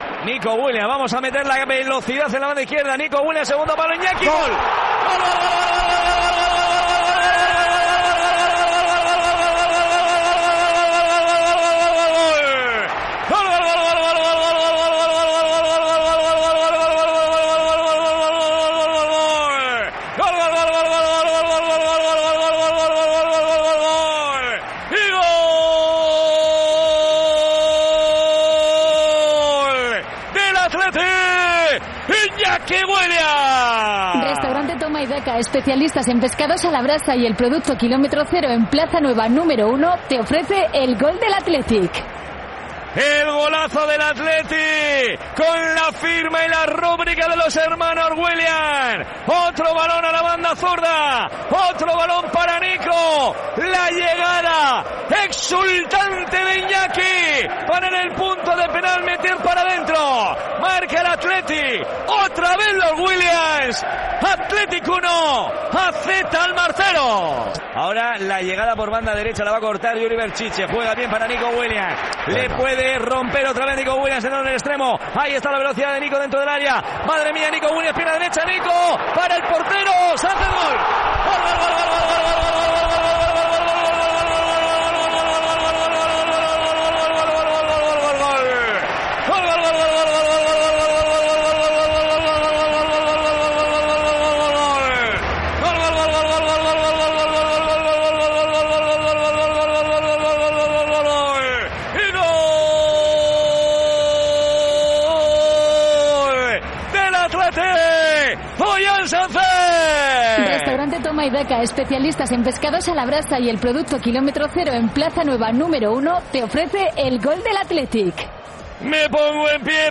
Partidos Athletic